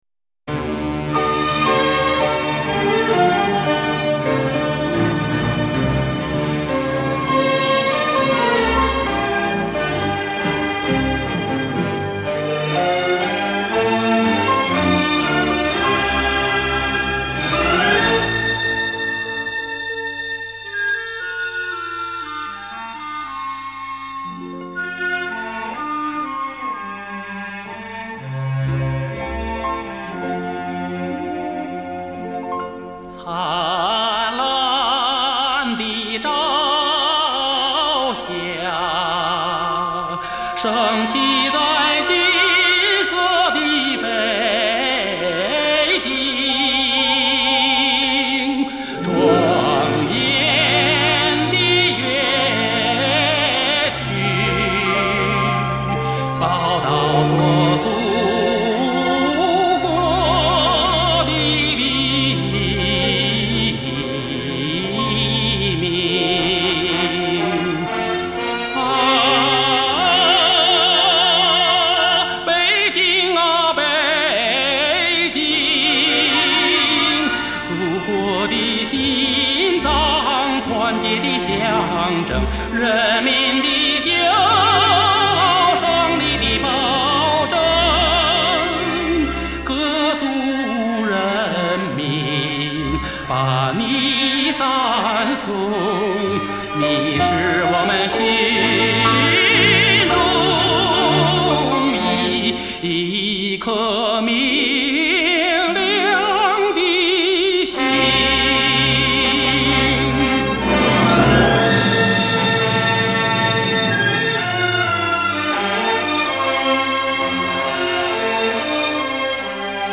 演唱者的迷人声线或甜美而不失骄媚，或自然而更显醇厚，共您回想当年的点滴真情，伴您在缕缕缠绵中回味如歌往事，感悟百味人生。